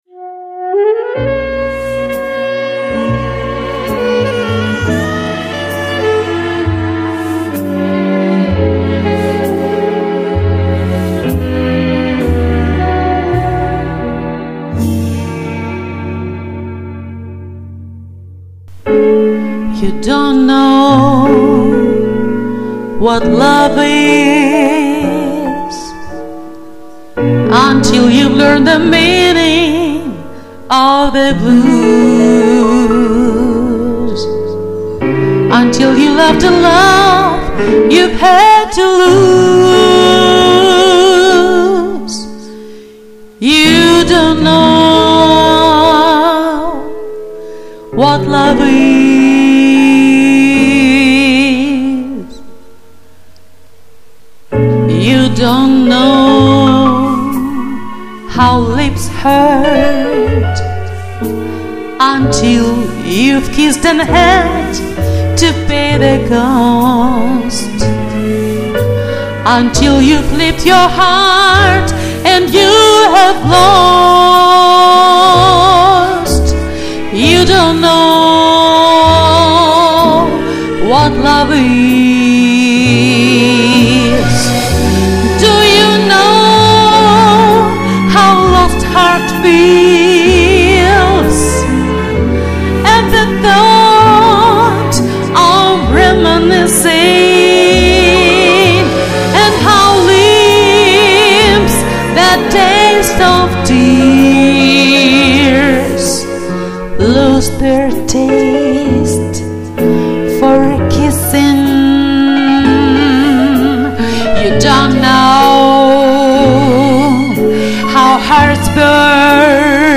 голос , конечно, звучит так сильно, распето-разогрето